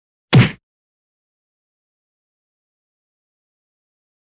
赤手空拳击中肉体15-YS070524.mp3
通用动作/01人物/03武术动作类/空拳打斗/赤手空拳击中肉体15-YS070524.mp3
• 声道 立體聲 (2ch)